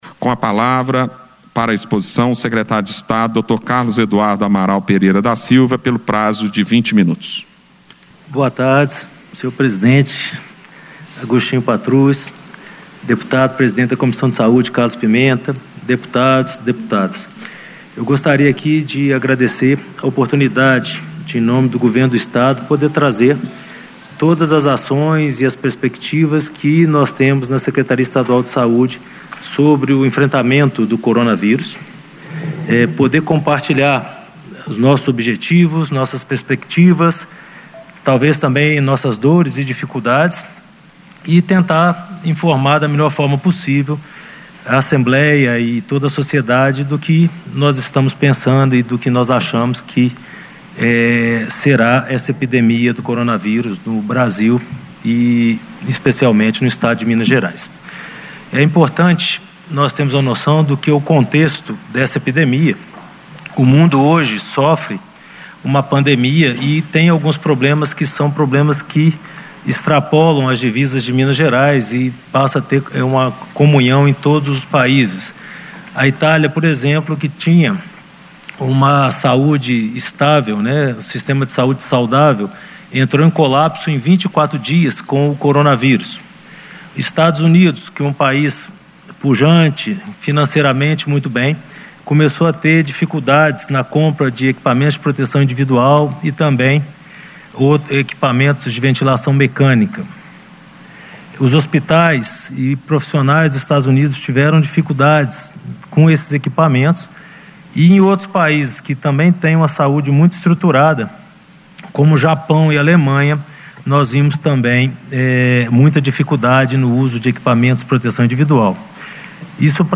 Durante audiência no Plenário, nesta quinta-feira (2/4), Carlos Eduardo Amaral fez uma explanação inicial sobre o cenário do Coronavírus em Minas, e destacou o apoio do Legislativo na aprovação de leis importantes para combater a pandemia mundial.
Discursos e Palestras